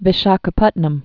(vĭ-shäkə-pŭtnəm)